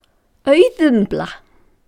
Auðumbla is our life-sustaining cow. (pronounce euth-eumbla, accent on the first syllable) She nourishes all life.
Listen to pronunciation: Auðumbla Please note that the accent is _always_ on the first syllable in Icelandic